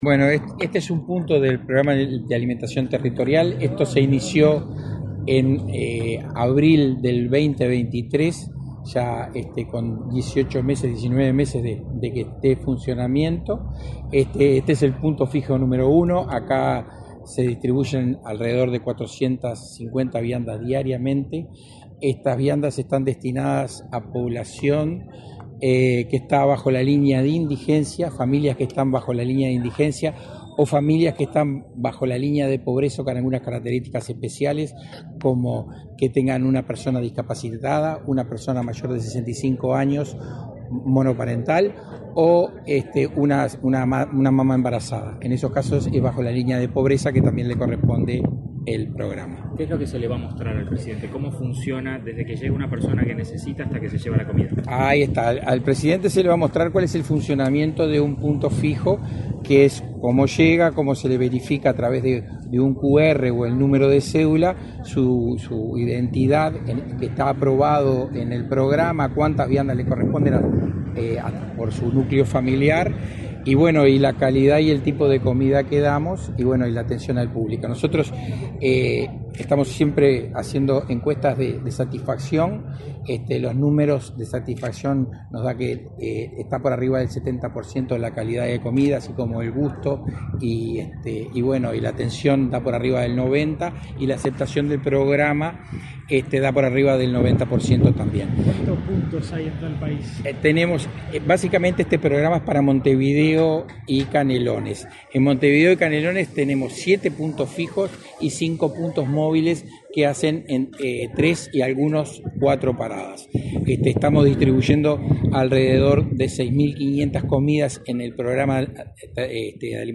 Declaraciones del director del INDA, Ignacio Elgue
El director del Instituto Nacional de Alimentación (INDA), Ignacio Elgue, dialogó con la prensa, antes de recibir al presidente Luis Lacalle Pou,